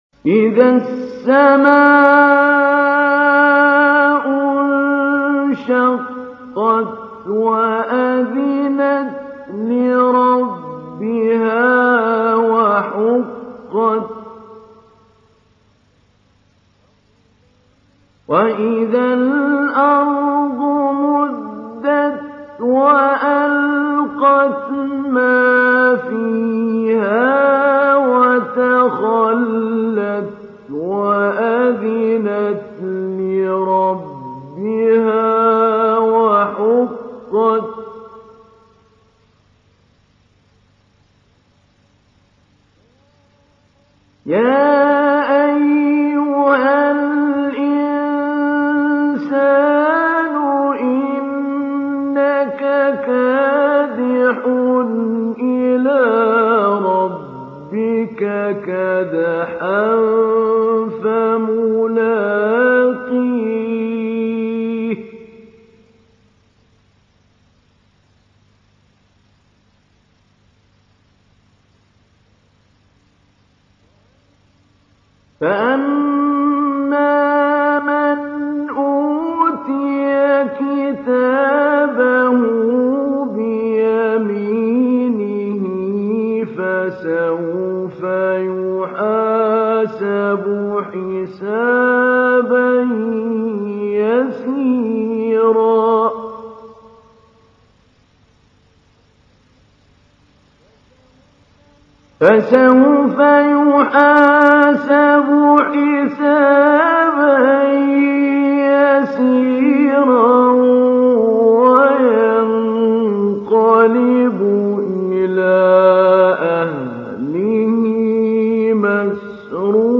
تحميل : 84. سورة الانشقاق / القارئ محمود علي البنا / القرآن الكريم / موقع يا حسين